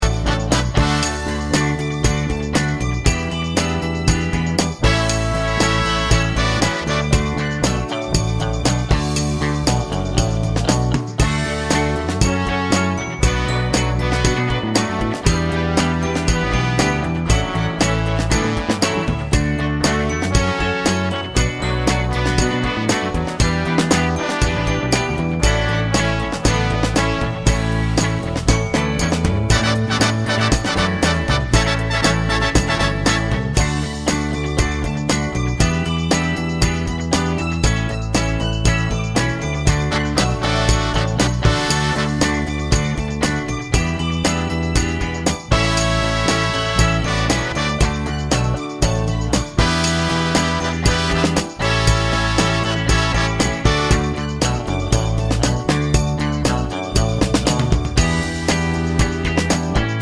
no Backing Vocals Soul / Motown 3:14 Buy £1.50